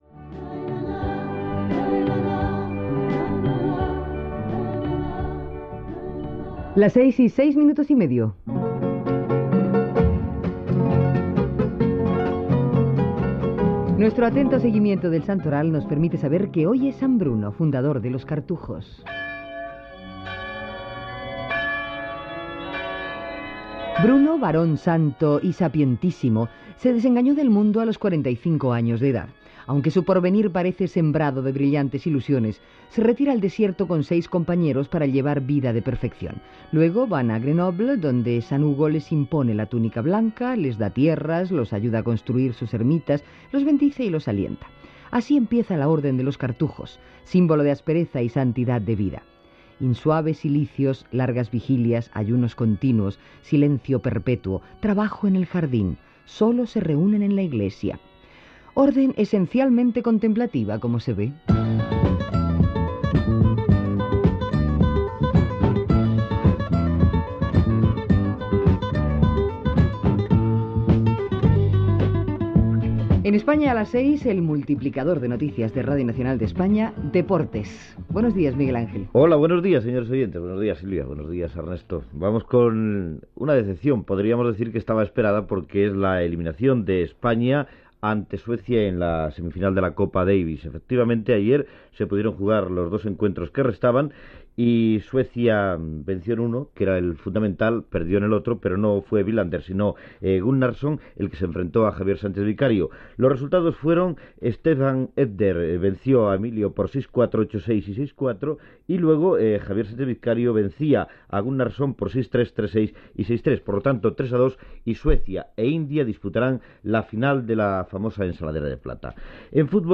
Gènere radiofònic Informatiu